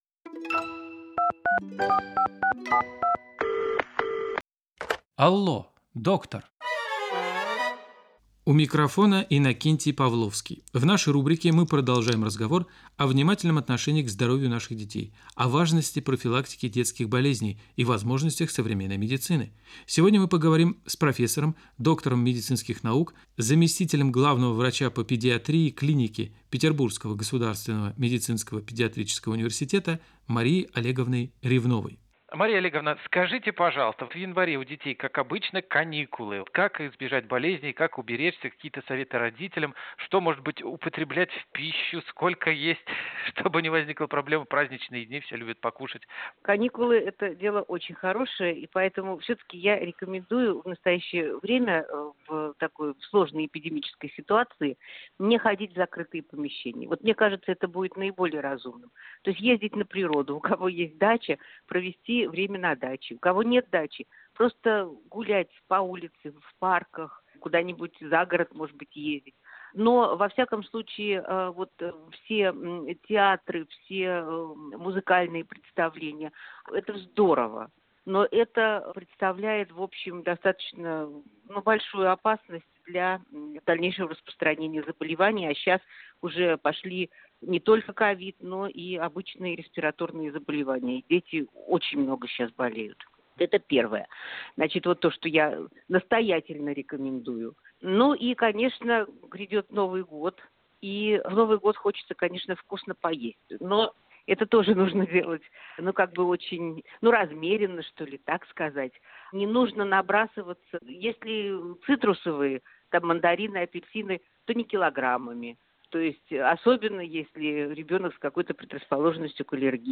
в эфире программы «Алло Доктор» на радио «Петербург»